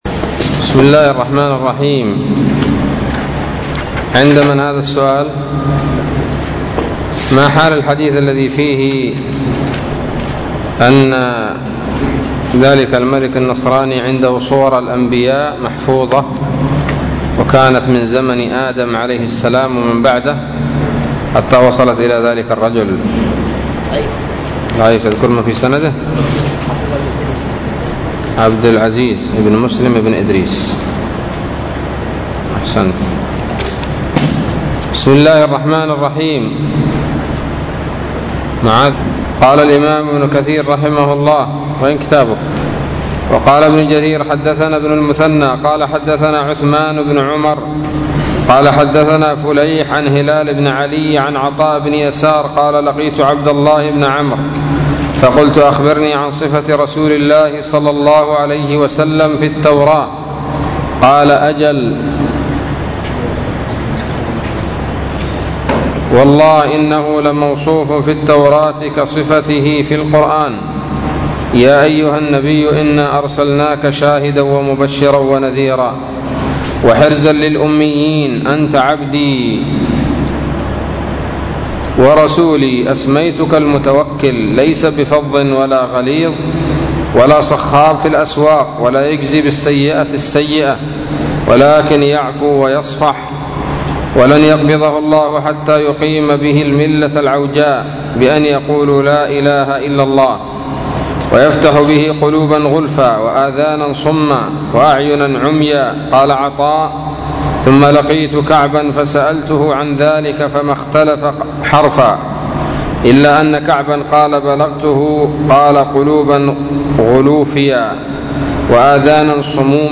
الدرس الحادي والخمسون من سورة الأعراف من تفسير ابن كثير رحمه الله تعالى